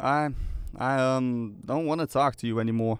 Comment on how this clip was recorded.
Update Voice Overs for Amplification & Normalisation